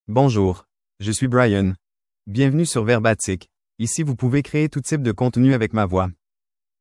MaleFrench (Canada)
BrianMale French AI voice
Voice sample
Brian delivers clear pronunciation with authentic Canada French intonation, making your content sound professionally produced.